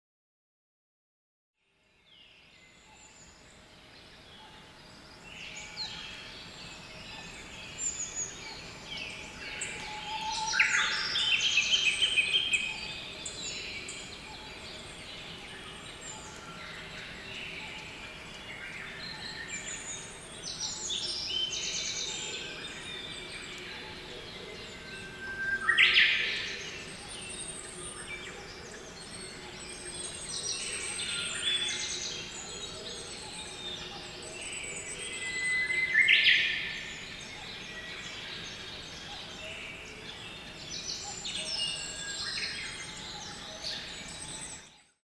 小鳥の声（※２）
・※２：『小鳥のさえずり / ネイチャー・サウンド・ギャラリー』（「小鳥のさえずり（戸隠森林植物園 水芭蕉園周辺）」／自然音）〔試聴用音源：dlns206_01.mp3〕権利者：株式会社デラ，製品番号：DLNS-206，参照：権利者提供音源